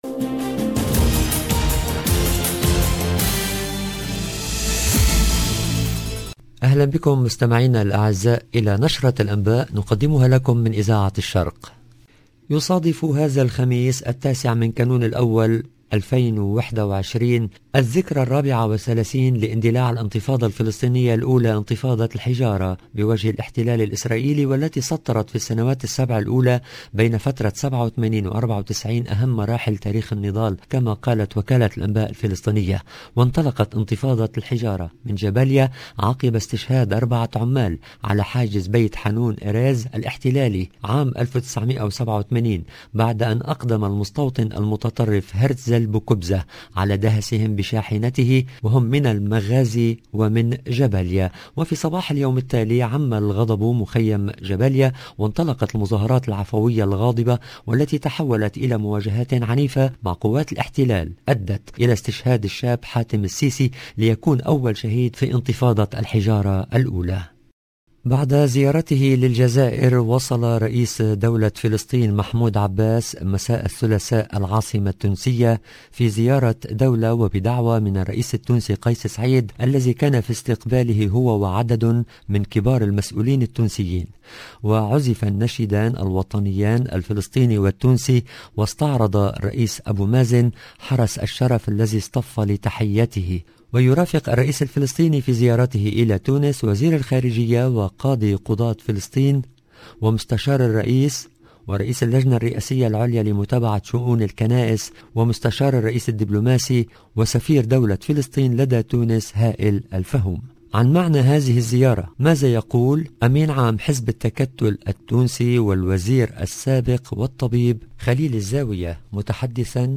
LE JOURNAL DU SOIR EN LANGUE ARABE DU 8/12/2021